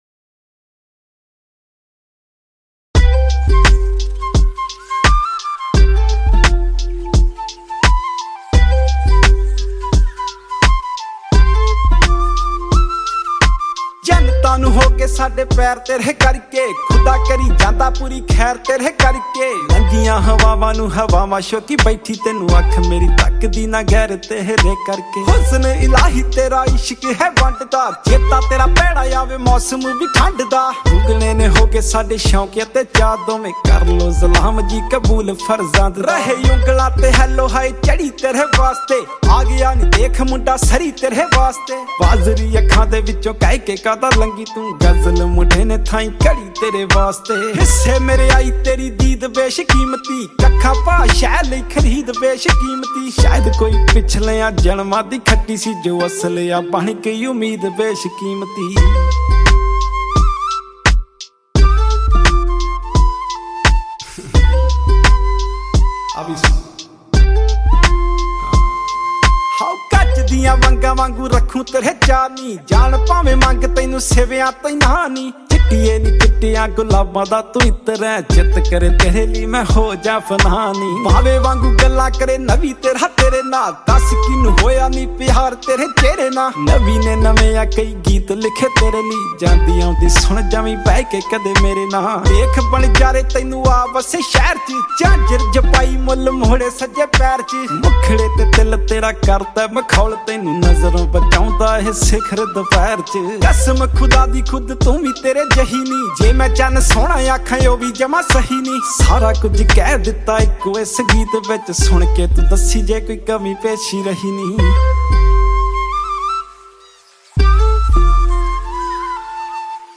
New Punjabi Song 2023